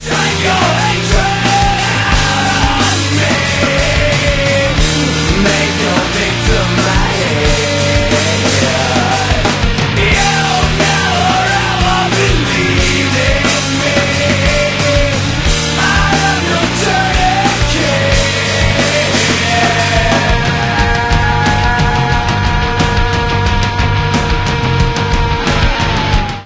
This is a sound sample from a commercial recording.
Reduced quality: Yes